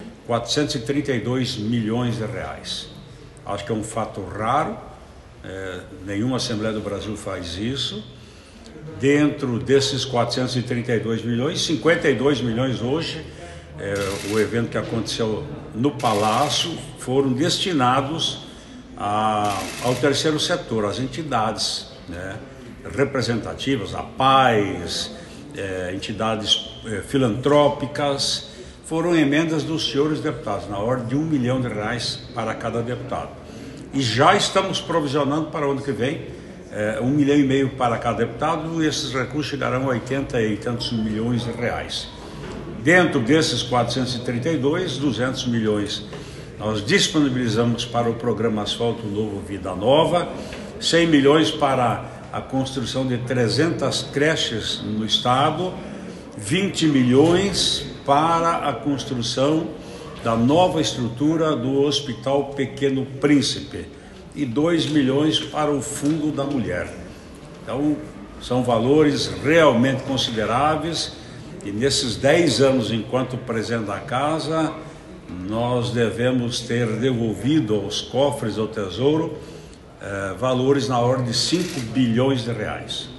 A Assembleia Legislativa do Paraná devolveu aos cofres públicos, somente em 2024, um total de R$ 432 milhões. O anúncio foi feito pelo presidente da Casa, deputado Ademar Traiano (PSD), em entrevista coletiva realizada antes da sessão plenária desta segunda-feira (25).